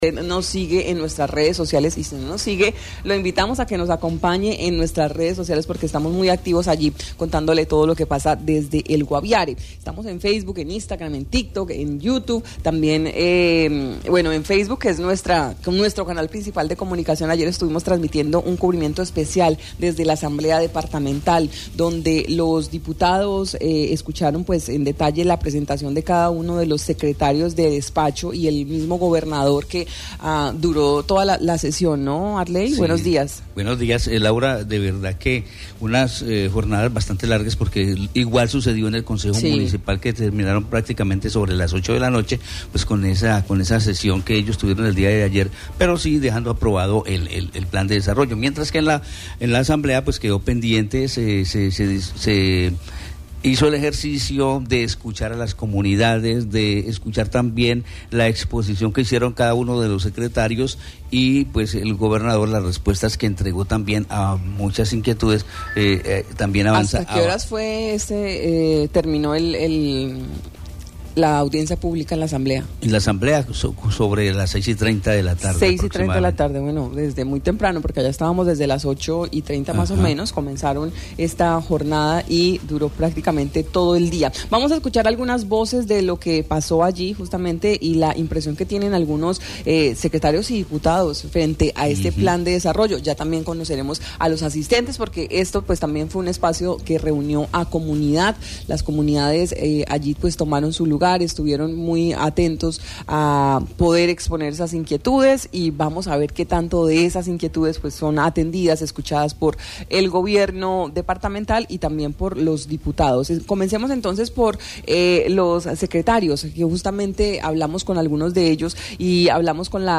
En el recinto de la Duma Departamental, el gobernador del Guaviare Yeison Rojas y los secretarios socializaron el plan de desarrollo para los próximos cuatro años.
Los diputados, secretarios de despacho, líderes de organizaciones y presidentes de Juntas de Acción Comunal entregaron en Marandua Noticias sus conceptos sobre los contenidos del Plan de Desarrollo Guaviare avanza 2024 – 2027 del gobernador Yeison Rojas.